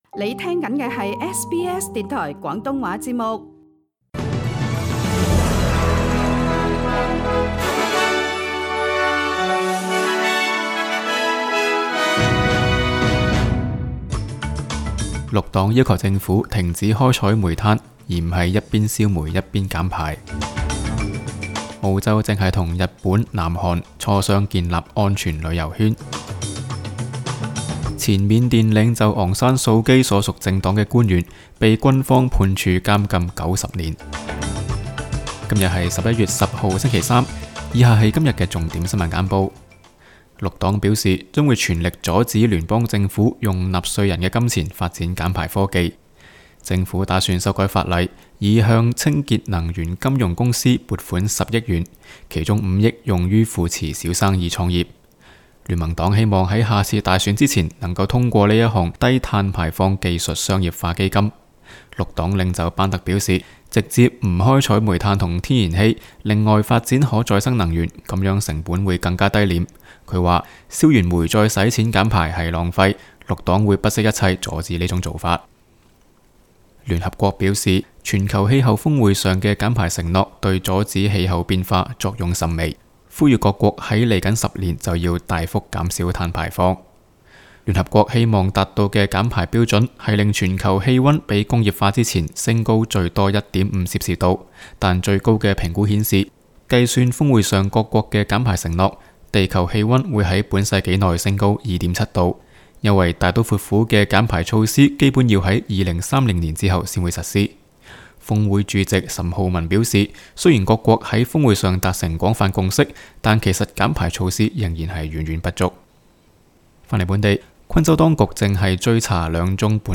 SBS 新聞簡報（11月10日）